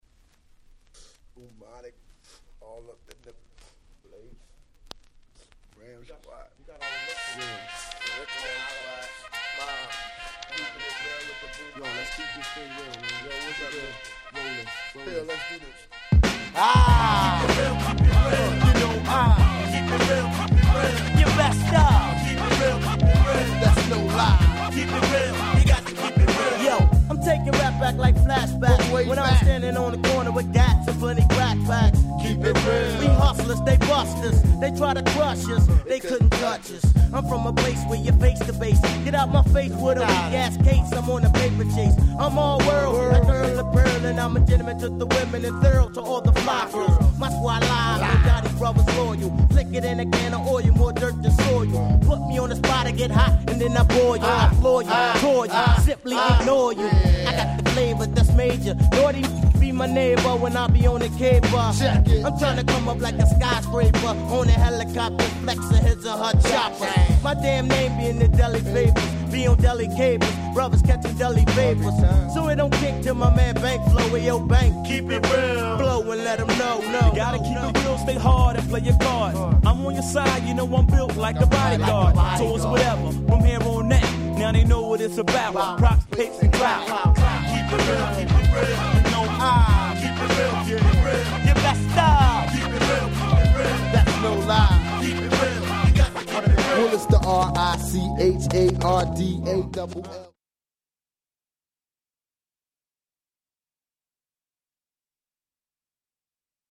95' Underground Hip Hop Classic !!
(Vocal)